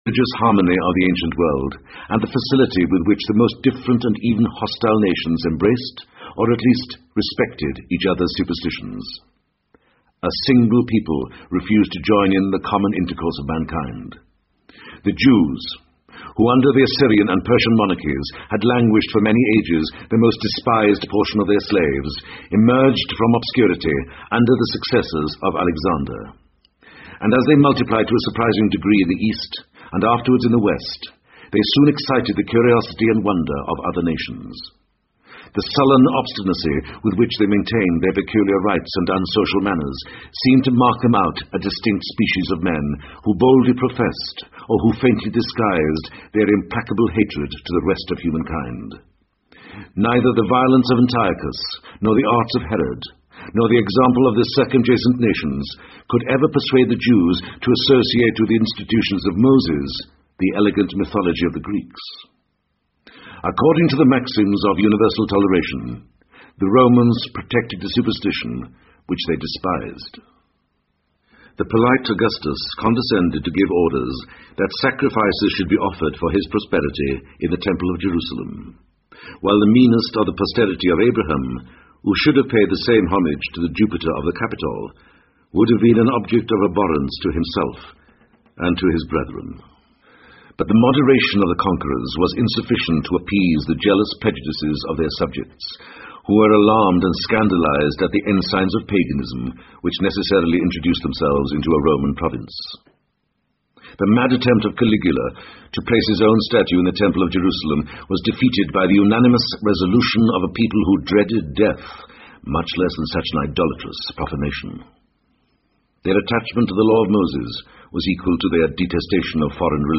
在线英语听力室罗马帝国衰亡史第一部分：49的听力文件下载,有声畅销书：罗马帝国衰亡史-在线英语听力室